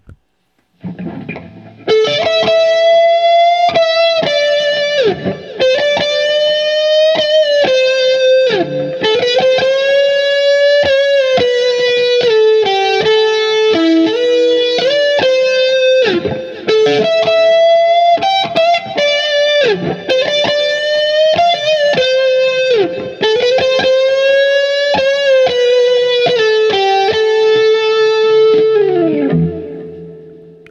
Speaker is a QSC K12.